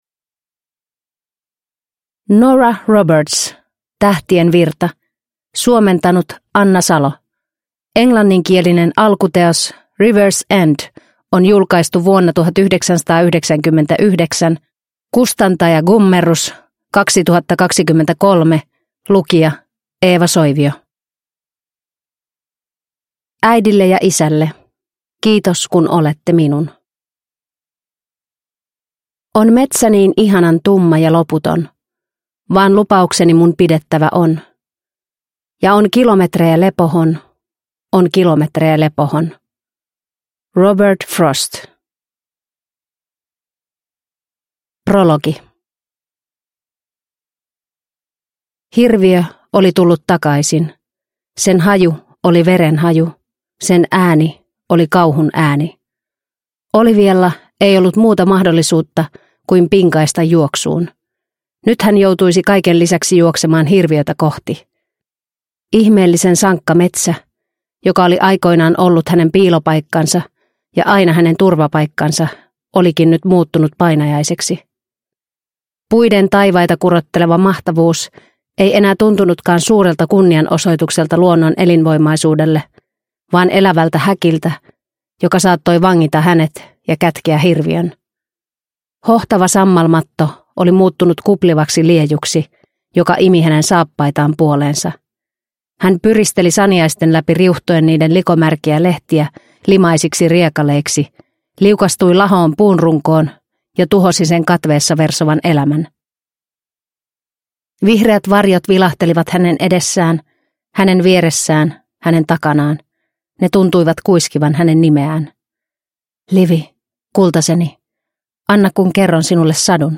Tähtien virta – Ljudbok – Laddas ner